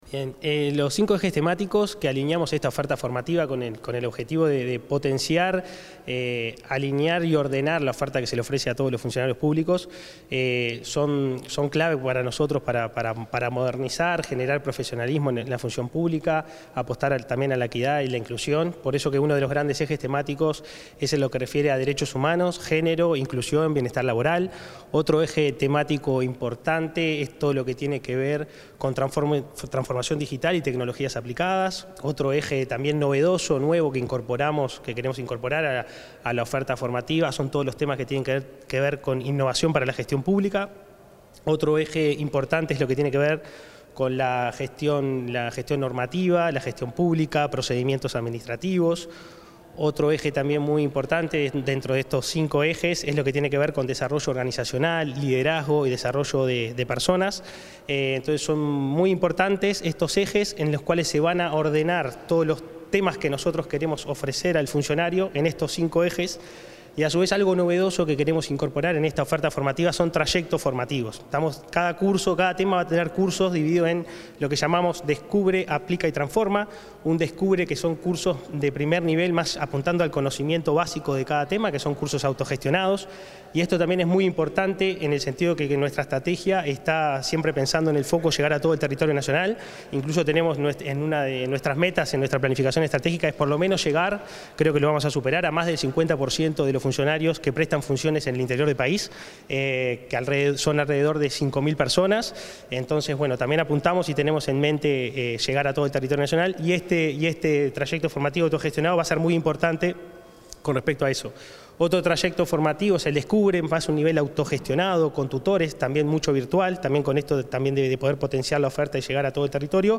Declaraciones del director de la ENAP, Bruno Minchilli
El director de la Escuela Nacional de Administración Pública (ENAP), Bruno Minchilli, realizó declaraciones, durante la presentación de la Estrategia